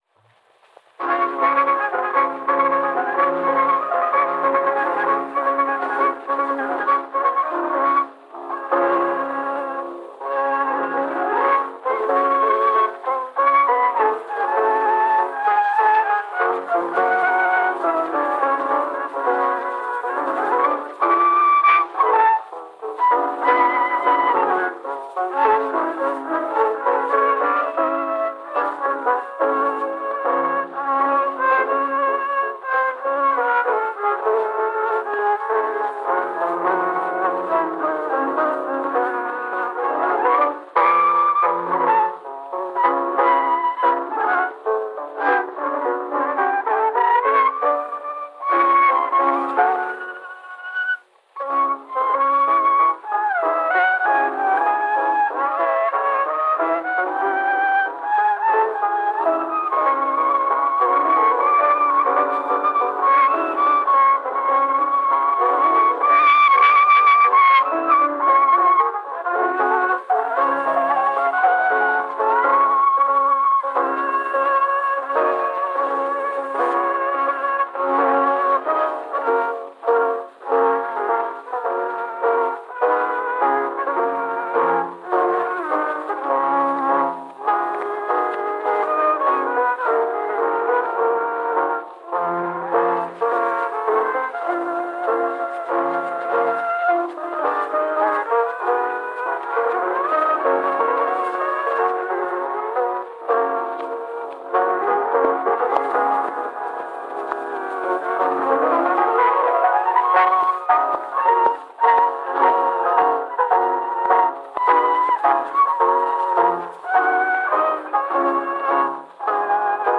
[Toreadorens sang spillet på violin]
violin
flygel
med slutannoncering.
Rubenvalse